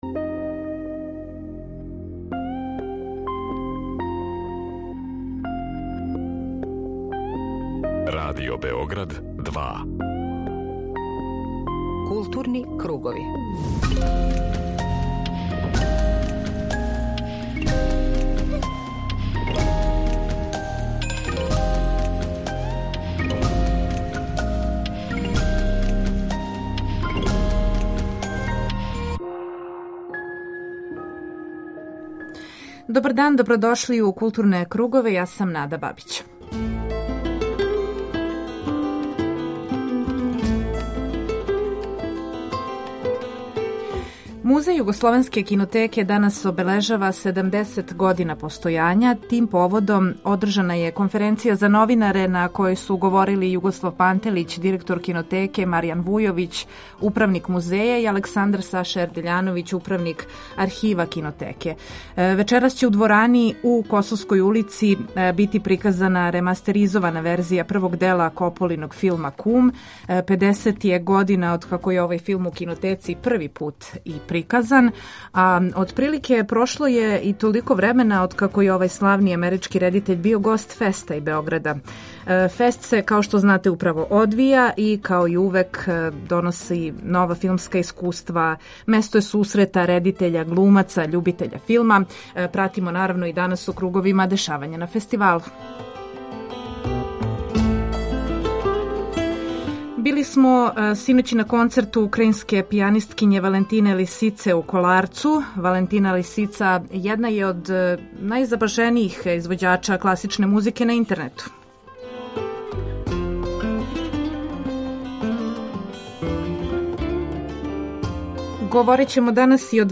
Група аутора Централна културно-уметничка емисија Радио Београда 2.